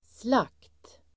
Uttal: [slak:t]